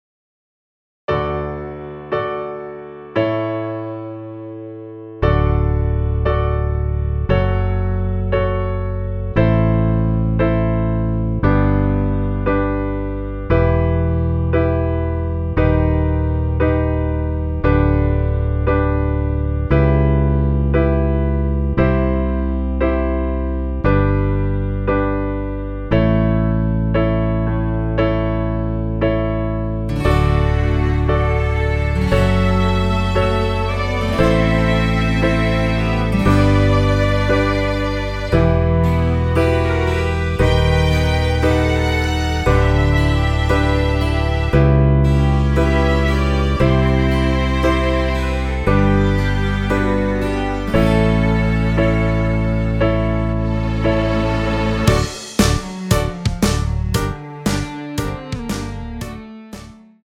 MR입니다.
원키에서(+5)올린 MR입니다.
앞부분30초, 뒷부분30초씩 편집해서 올려 드리고 있습니다.